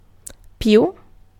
Ääntäminen
Tuntematon aksentti: IPA: /ˈei/